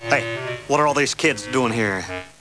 He's big. He's strong. He talks like Elvis and thinks he's god's gift to women... What's not to like?